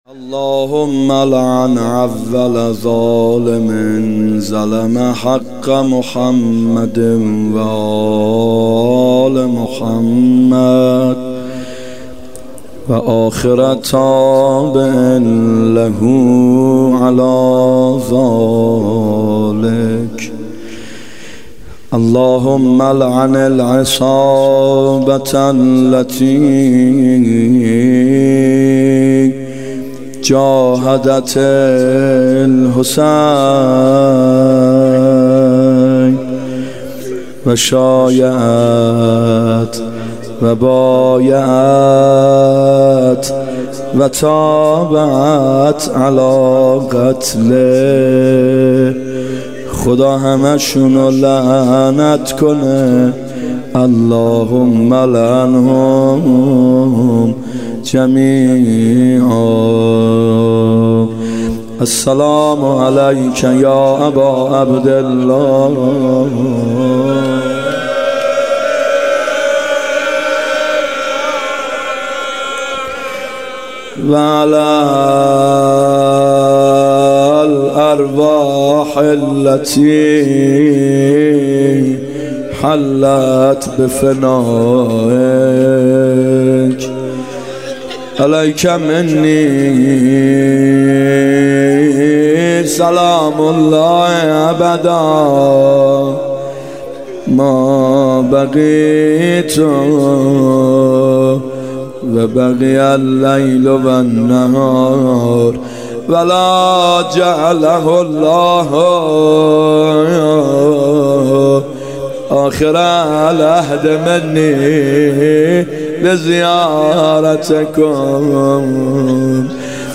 محرم 94 شب دهم روضه ( اگر کشتن چرا ابت ندادن)
محرم 94(هیات یا مهدی عج)